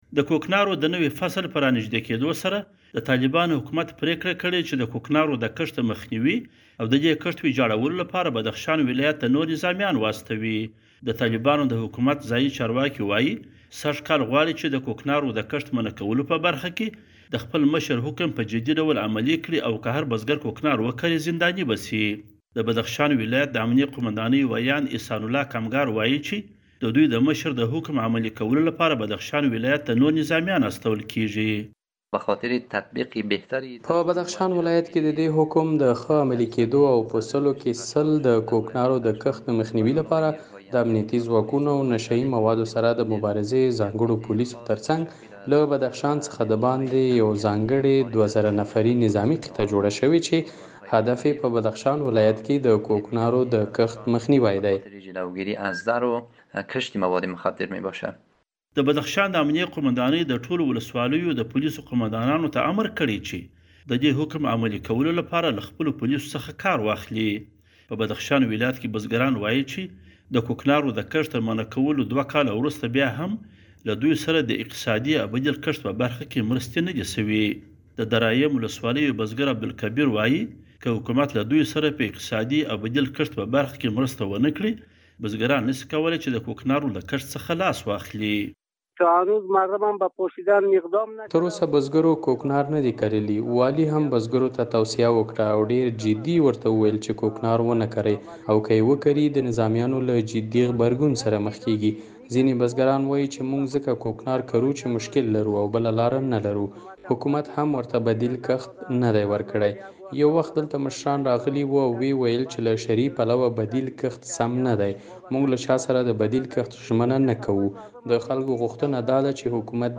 د بدخشان د کوکنارو په اړه راپور